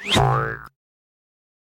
blink.ogg